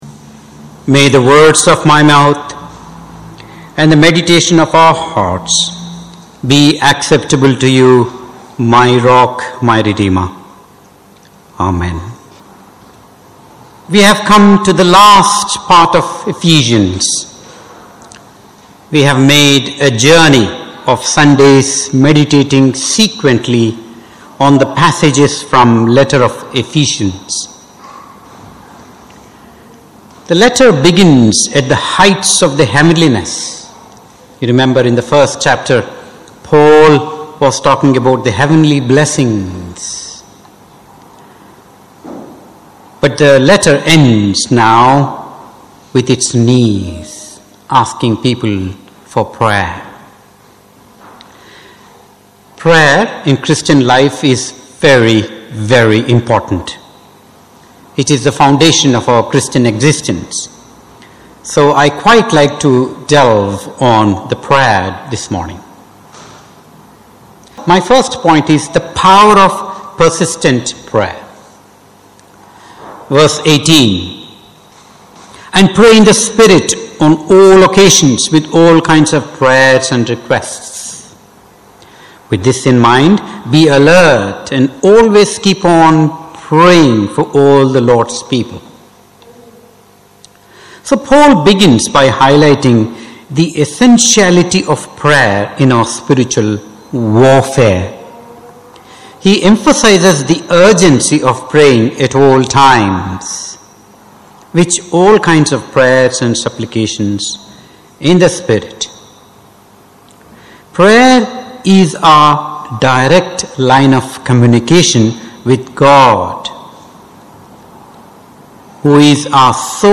Ephesians Passage: Ephesians 6:18-24 Service Type: Morning Service « The Full Armour of God Post Easter Theme